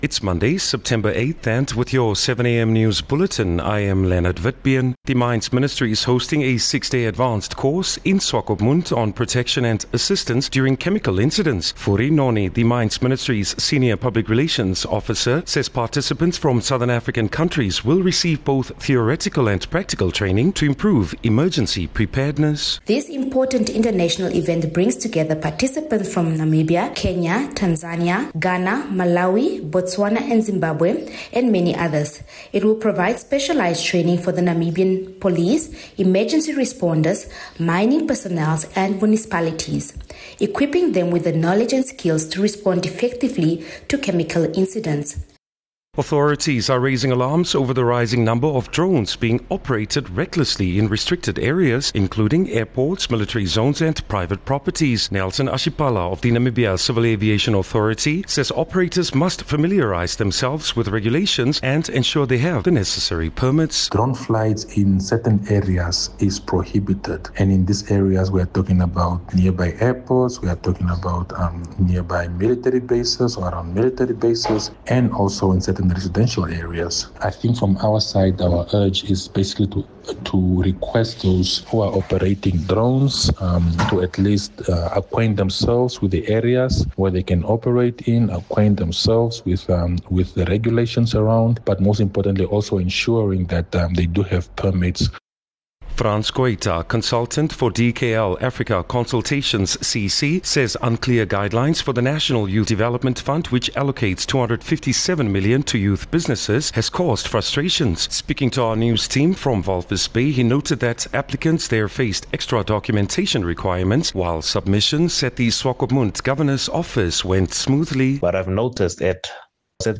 8 Sep 8 September-7am news